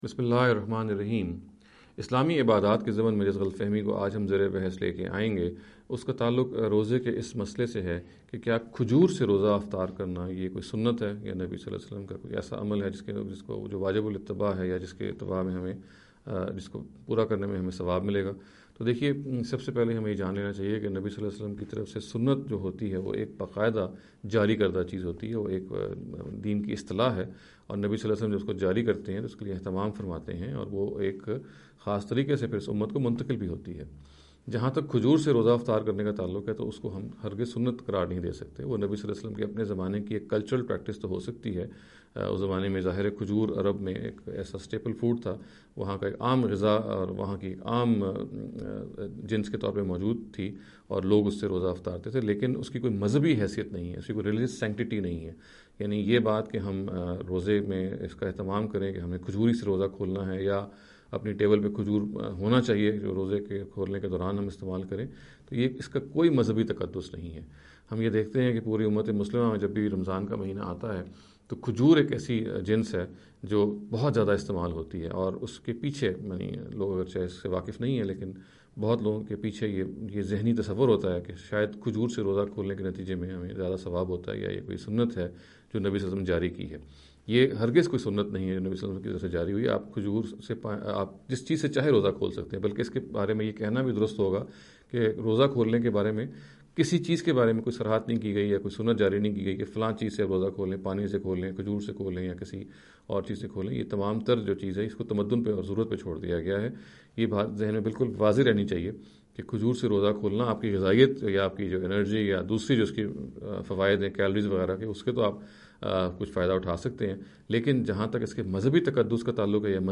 In every lecture he will be dealing with a question in a short and very concise manner. This sitting is an attempt to deal with the question 'Is It a Sunnah to Break the Fast with Dates?’.